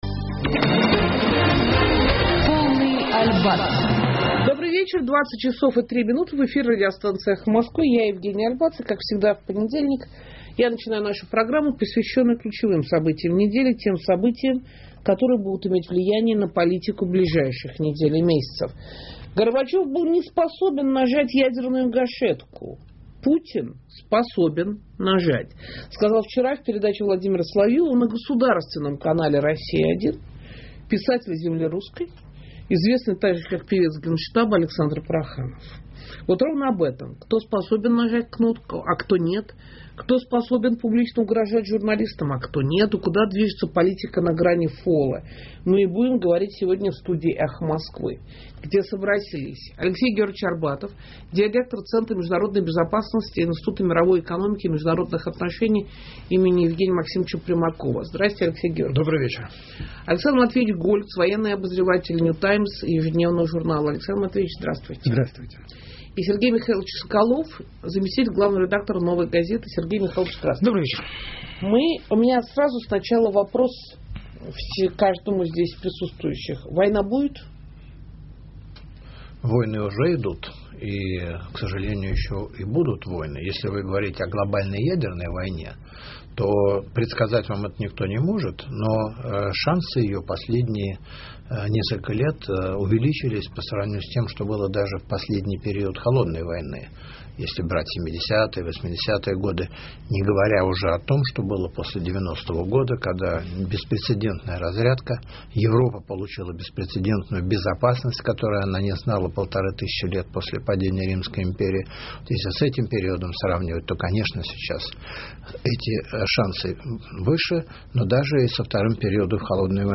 Мы и будет говорить сегодня в студии «Эхо Москвы».